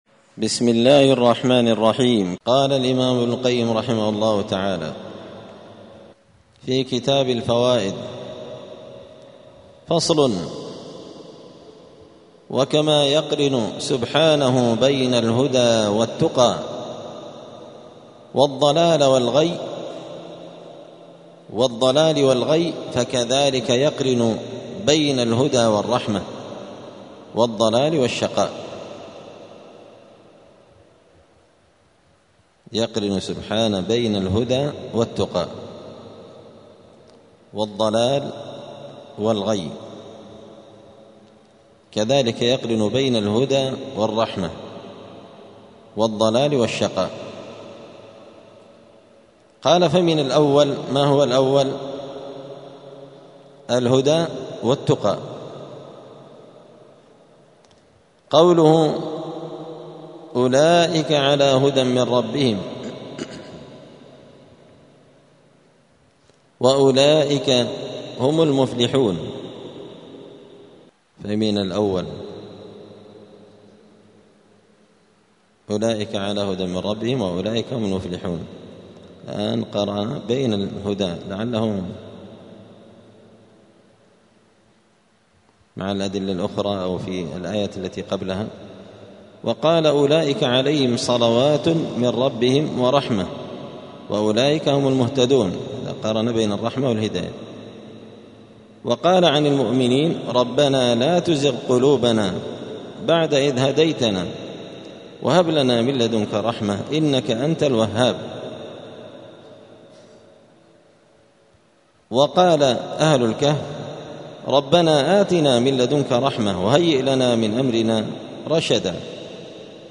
الأحد 4 محرم 1447 هــــ | الدروس، دروس الآداب، كتاب الفوائد للإمام ابن القيم رحمه الله | شارك بتعليقك | 8 المشاهدات